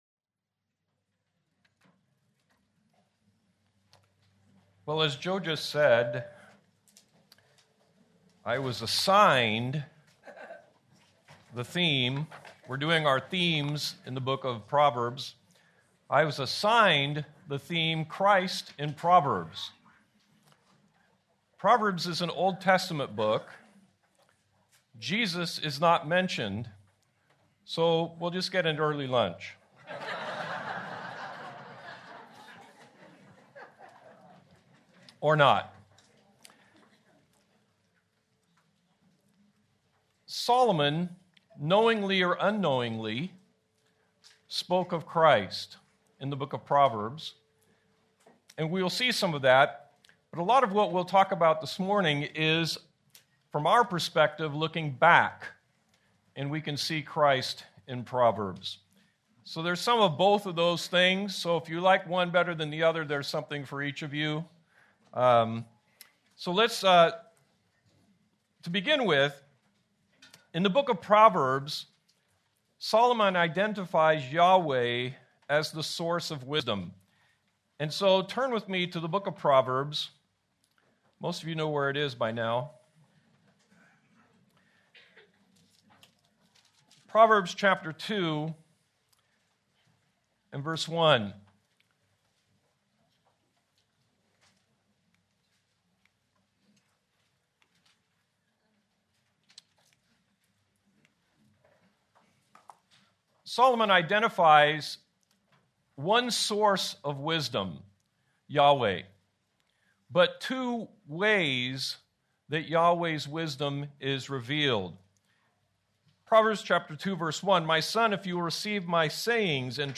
February 8, 2026 - Sermon
Please note, due to technical difficulties, this recording skips brief portions of audio.